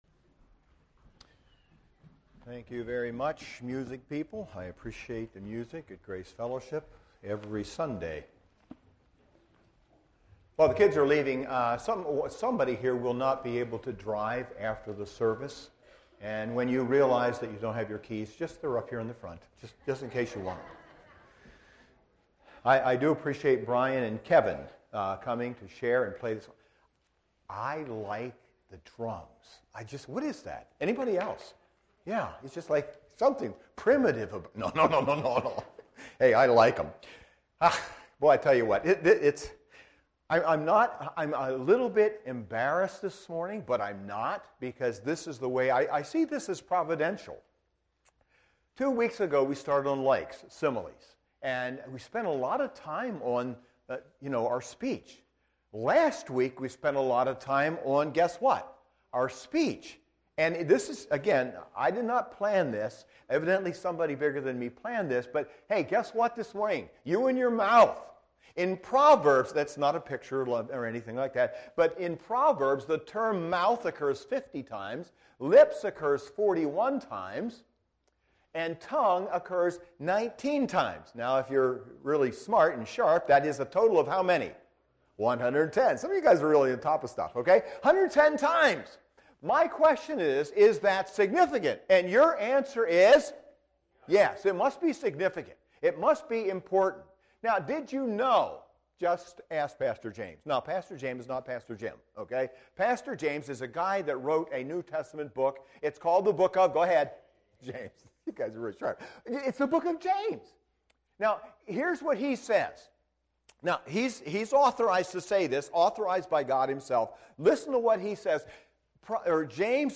SERMONS - Grace Fellowship Church